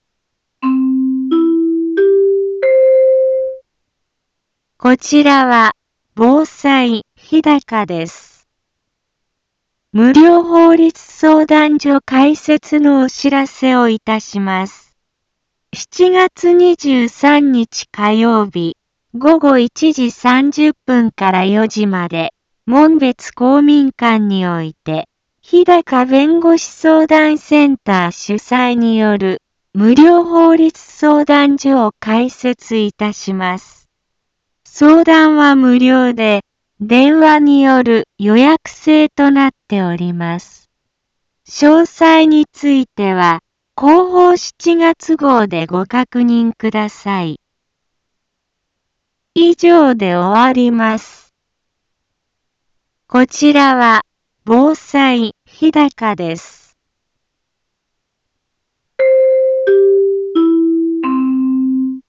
Back Home 一般放送情報 音声放送 再生 一般放送情報 登録日時：2019-07-18 10:02:15 タイトル：無料法律相談所開設のお知らせ インフォメーション：こちらは、防災日高です。 無料法律相談所開設のお知らせをいたします。